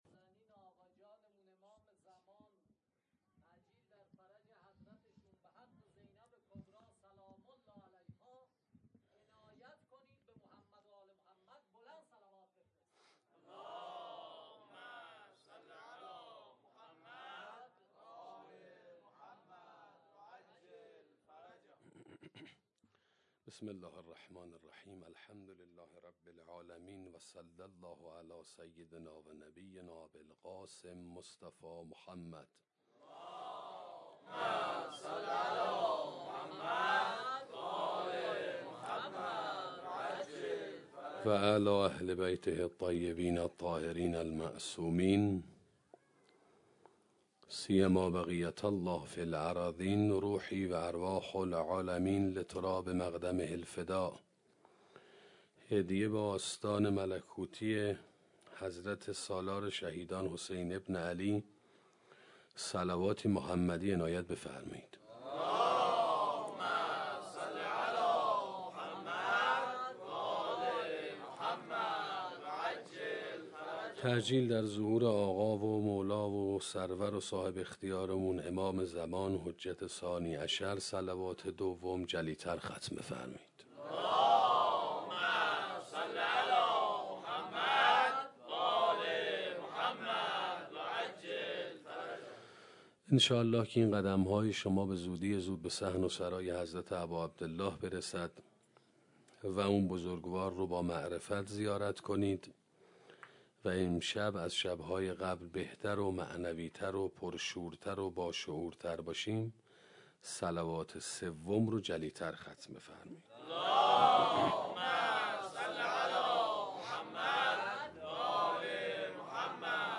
سخنرانی عاشورا و انسان حداکثری 2